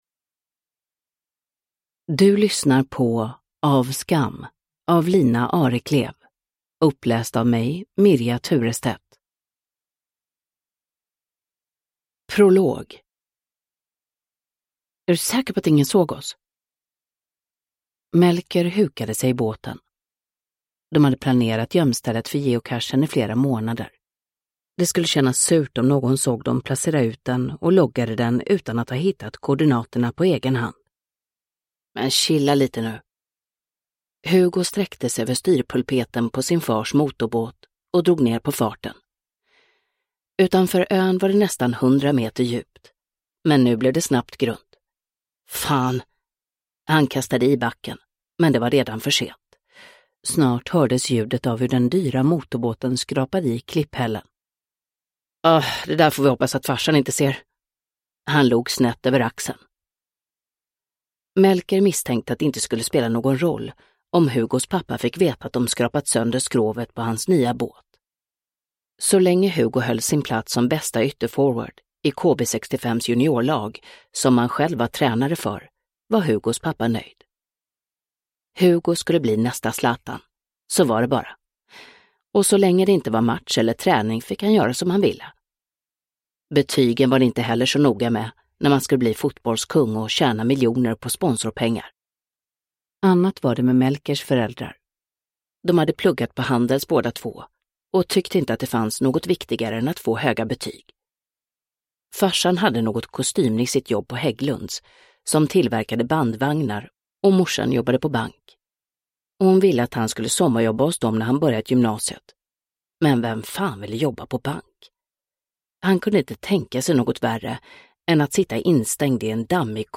Uppläsare: Mirja Turestedt